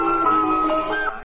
flute nș 3
flute3.mp3